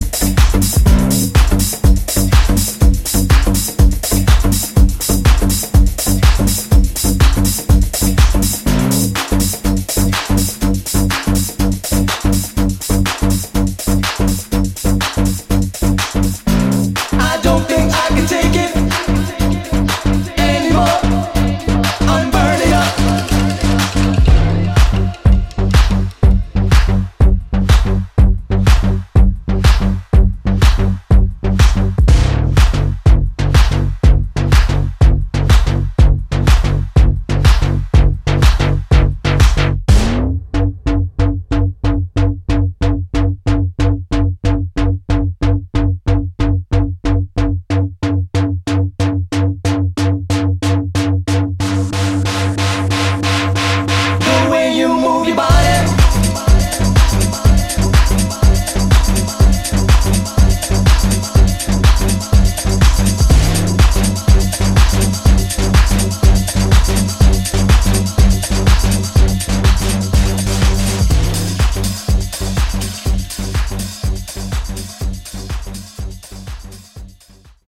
シャッフルしたリフをフックに据えたインパクト大の
いずれもミニマルでありながらも捻りの効いた内容で、ディープ・ハウス路線のセットで助けられることも多いはず。